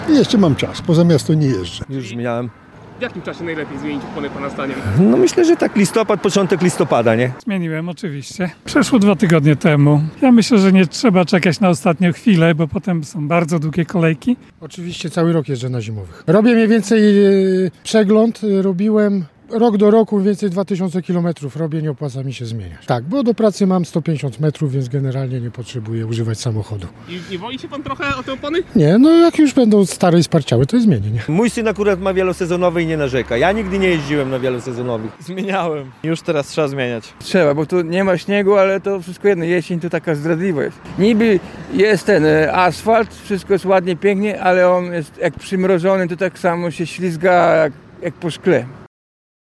Sonda: Czy mieszkańcy Łomży zmienili już opony na zimowe?
Zapytaliśmy mieszkańców Łomży czy ich zdaniem, taka zmiana jest w ogóle potrzebna.